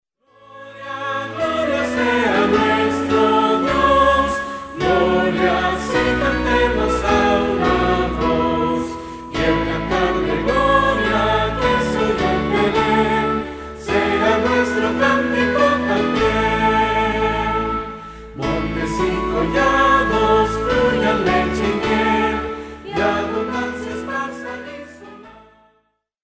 que presenta himnos tradicionales con un enfoque fresco